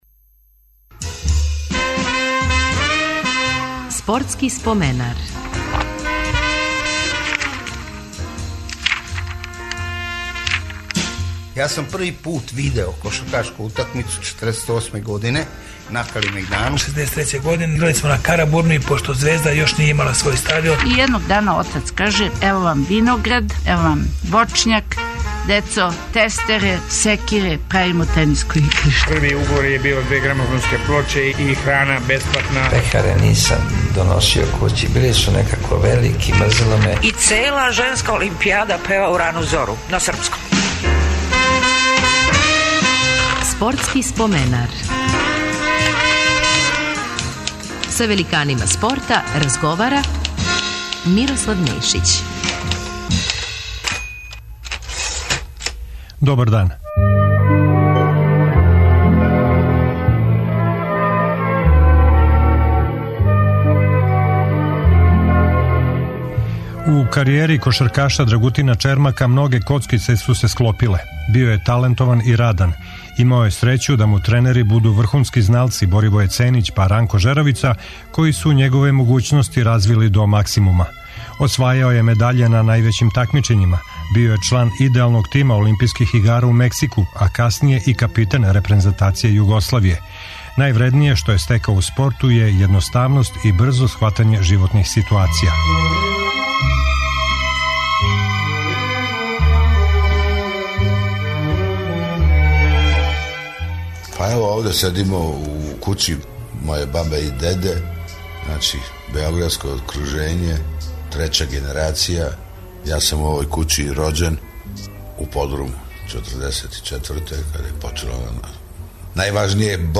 Гост нам је кошаркаш Драгутин Чермак. Играчку каријеру је почео у београдском Радничком, наставио у Партизану, а био је професионалац и у Холандији.